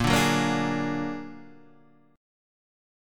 Bb+7 chord